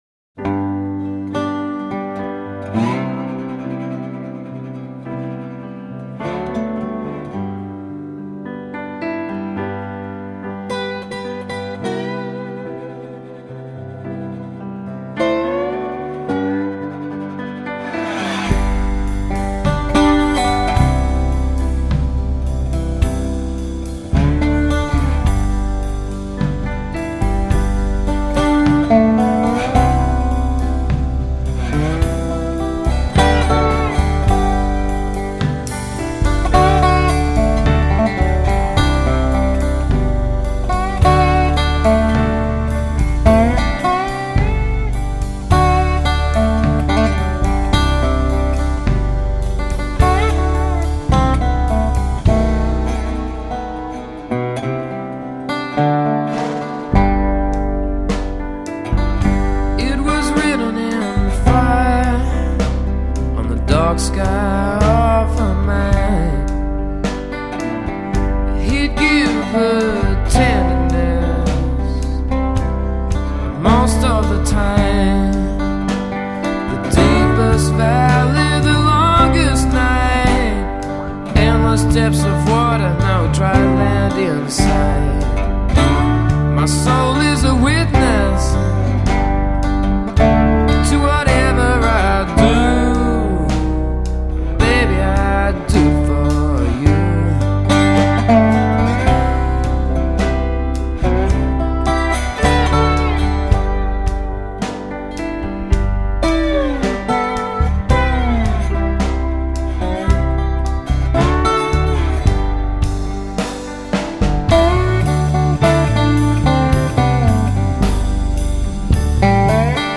acoustic guitar, lap steel, harmonica, dobro, vocals
drums
piano
bass
BEST AUSTRALIAN BLUES AND ROOTS ALBUM 2000